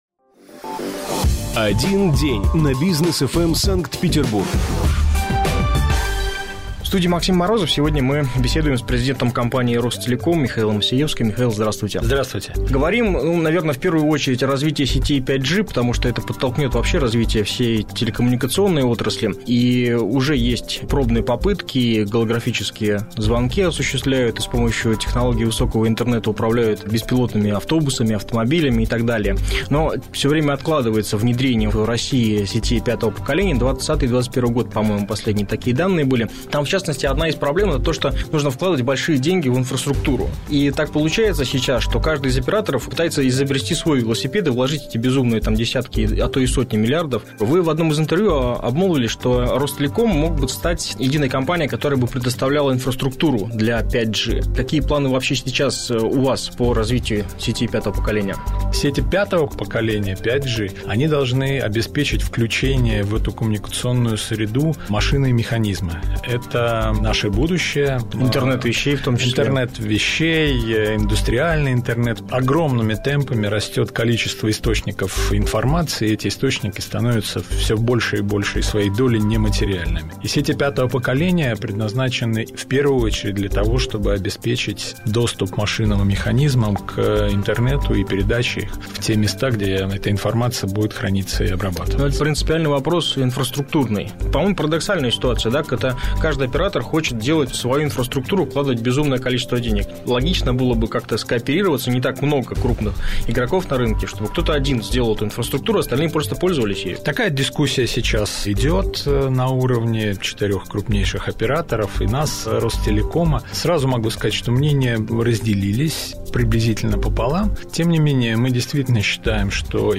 Гость программы: президент компании «Ростелеком» Михаил Осеевский